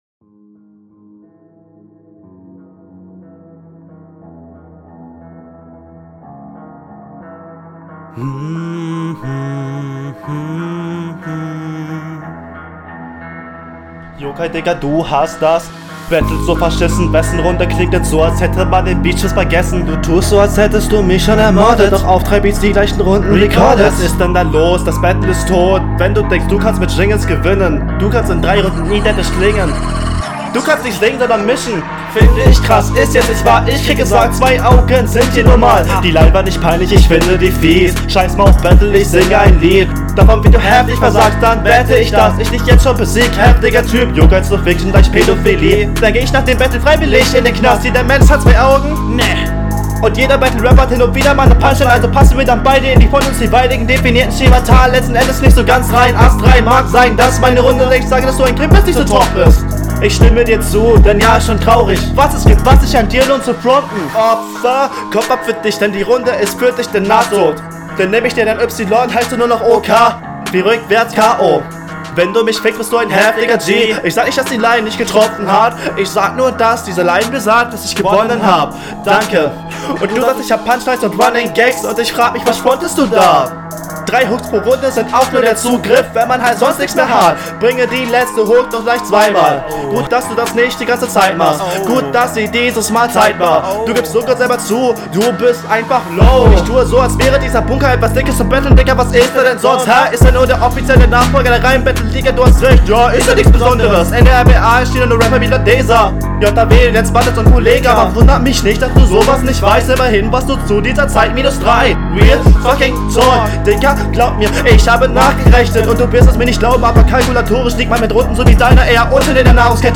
Soundqualität: Nicht ganz so smooth abgemischt und du klingst etwas so, als seist du zu …